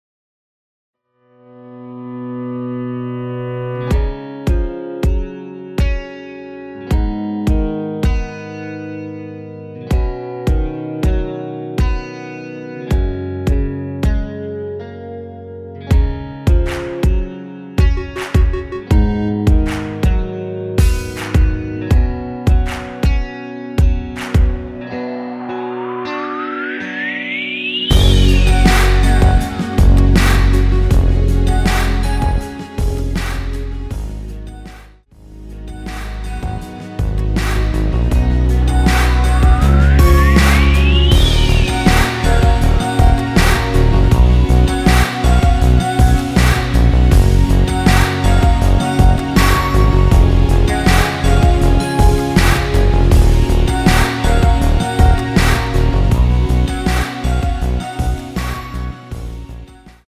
F#
◈ 곡명 옆 (-1)은 반음 내림, (+1)은 반음 올림 입니다.
앞부분30초, 뒷부분30초씩 편집해서 올려 드리고 있습니다.